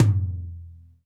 TOM RLTOM0IR.wav